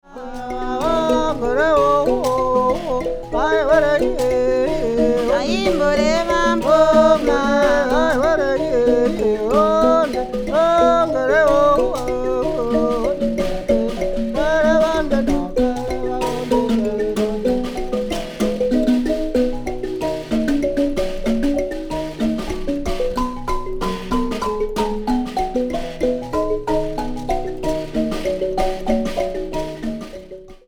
B pitch nyamaropa tuning.